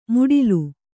♪ muḍilu